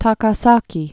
(täkä-säkē)